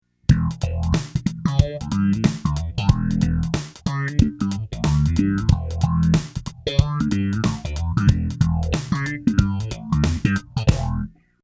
エフェクター・サウンド・サンプル
BOSS SE-70 Phaser
♪MP3 (ATELIER Z)
se70_phase.mp3